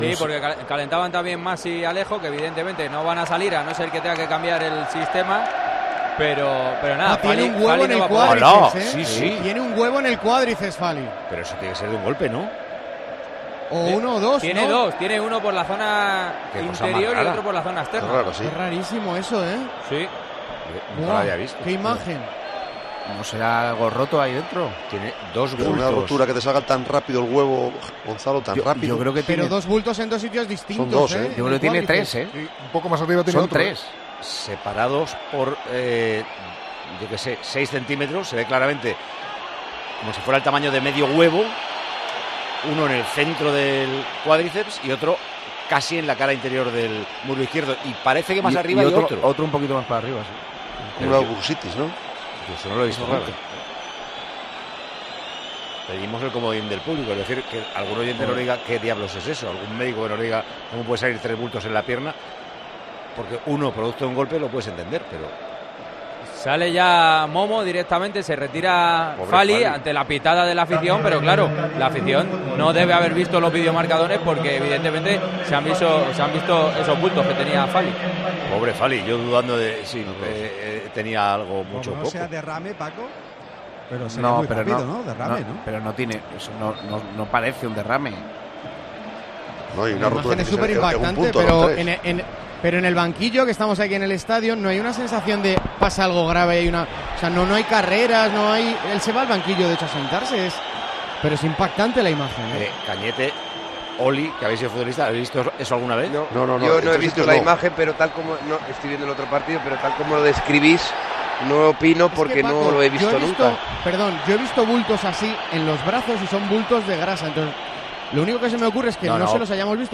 Durante la retransmisión de Tiempo de Juego asustó la imagen de Fali retirándose por lesión del Atlético de Madrid - Cádiz: tres grandes bultos sobresalían de su pierna.